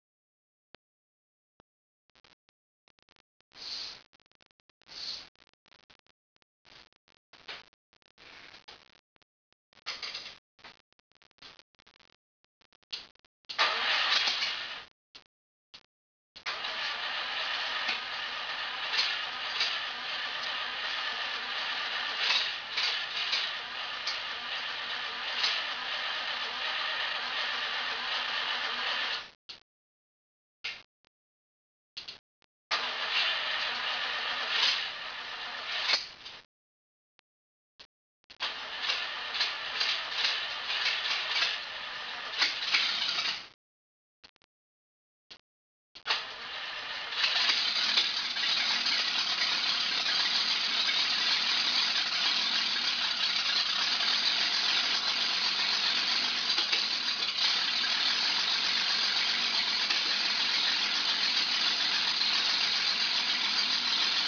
After getting it re-installed, I started it with just the 4 headers attached.  Ferrari music to my ears though the neighbors probably didn't care for the volume.